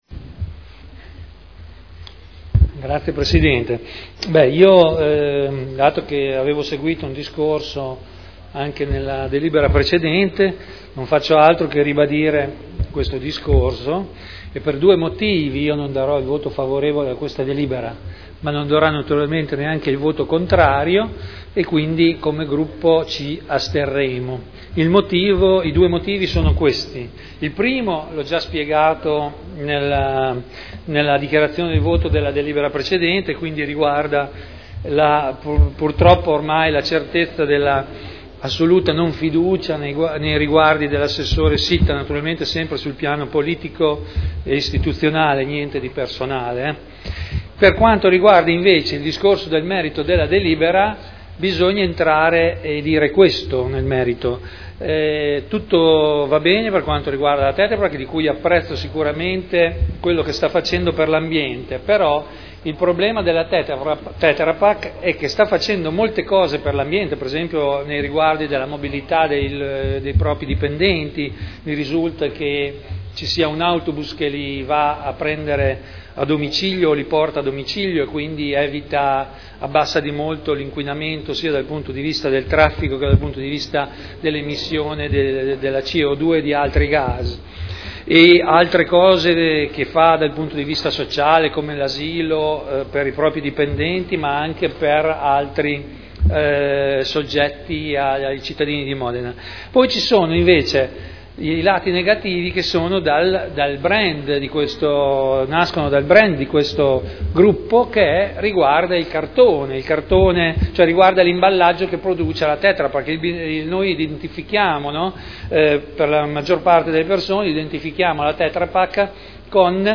Seduta del 30/05/2011. Dichiarazione di voto su proposta di deliberazione: Variante al POC-RUE – Area in via Emilia Ovest – Z.E. 1481-1502 – Approvazione